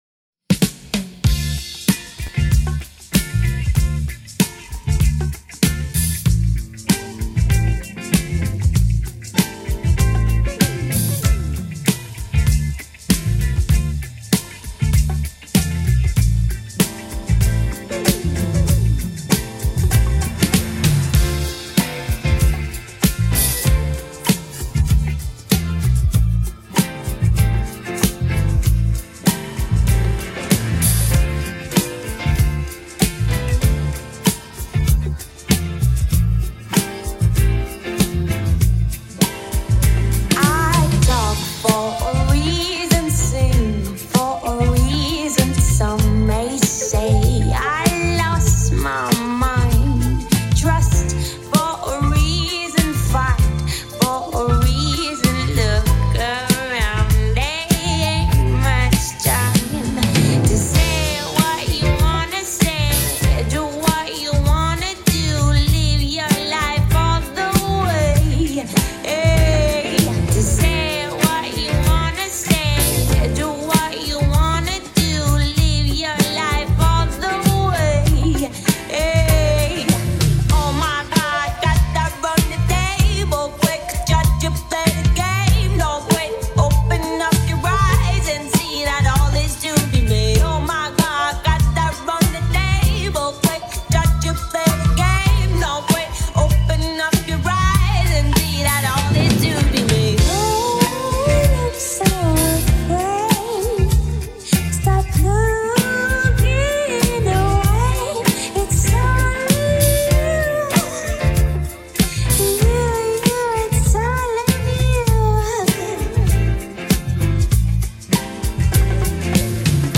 Instrumental
Acapella